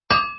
铁匠-点击屏幕增加锻造进度音效.mp3